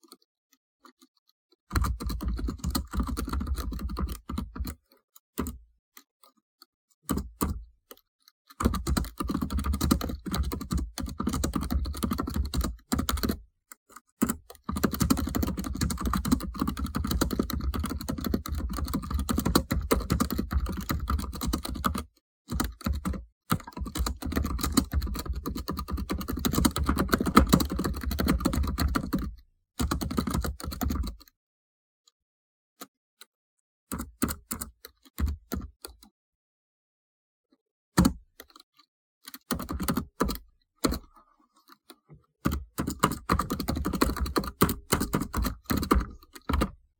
Typing (HP laptop)
button buttons computer data enter fast hack hacker sound effect free sound royalty free Sound Effects